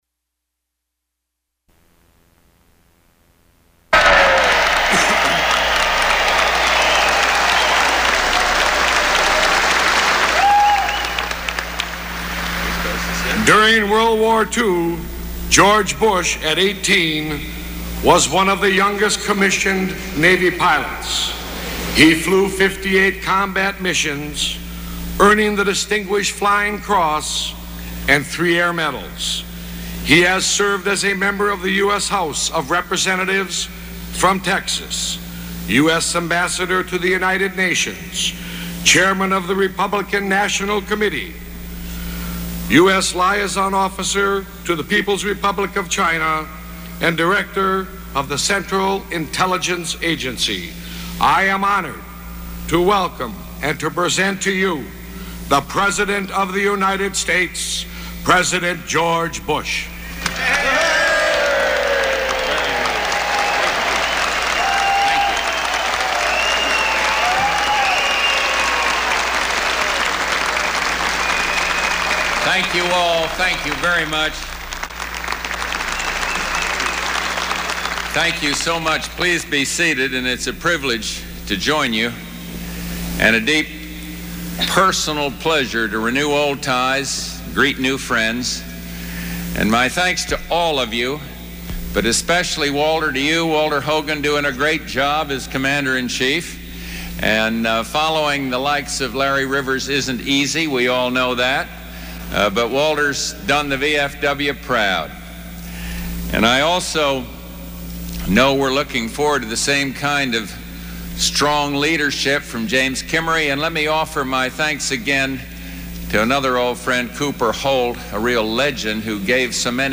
Subjects Iraq-Kuwait Crisis (1990-1991) Operation Desert Shield (1990-1991) Material Type Sound recordings Language English Extent 00:27:00 Venue Note Broadcast on CNN, August 20, 1990.